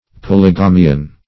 Polygamian \Pol`y*ga"mi*an\